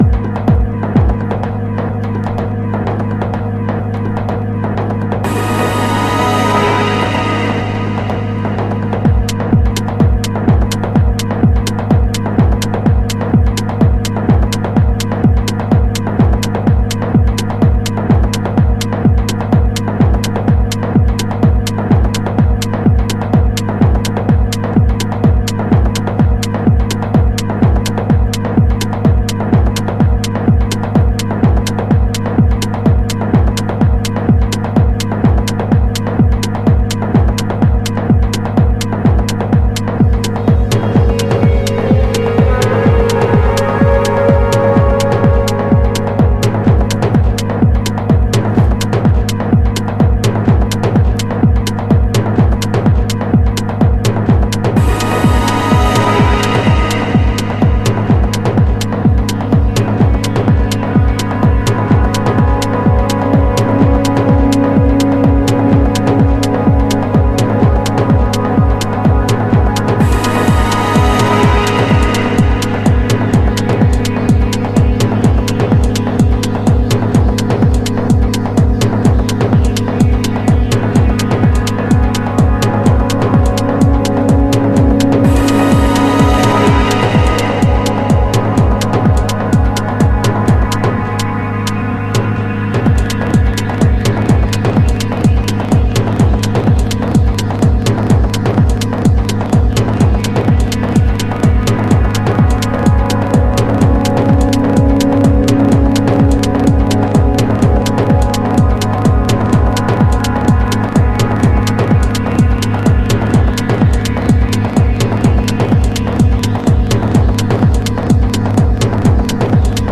信頼のパッドシンセワークは健在。
House / Techno